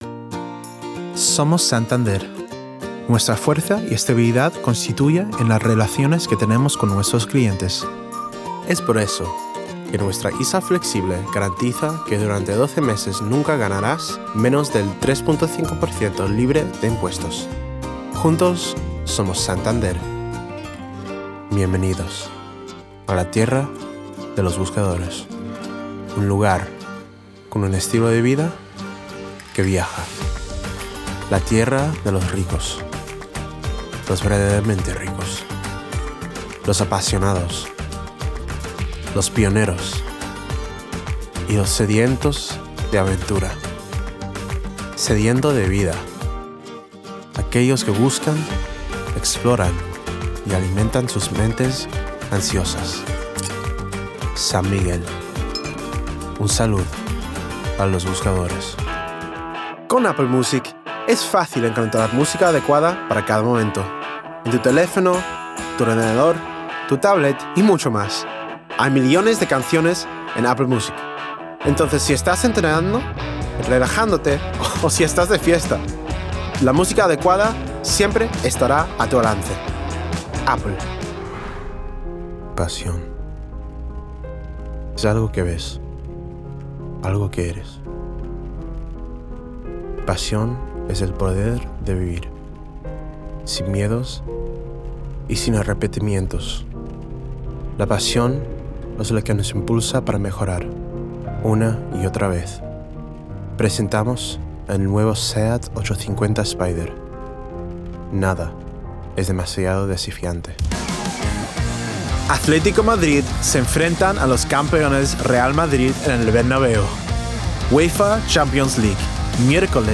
Spanish Reel
Commercial, Cool, Smooth, Confident, Friendly